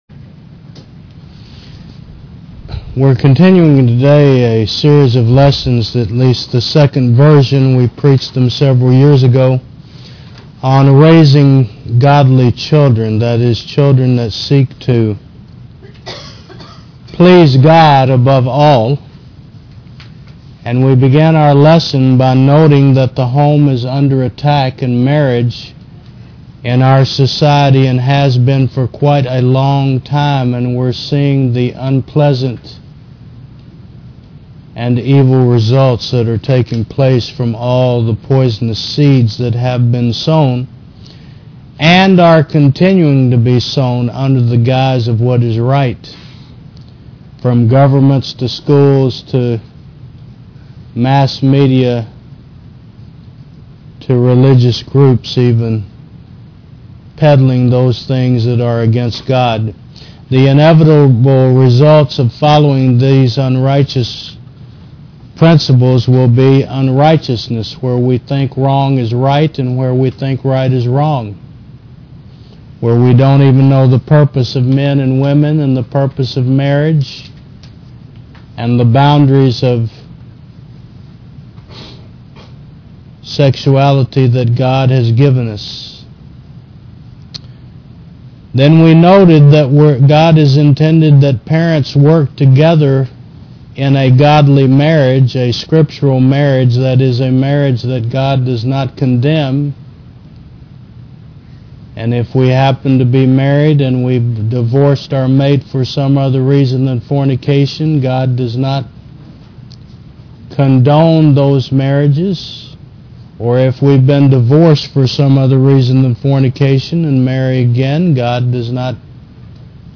Service Type: Sun. 11 AM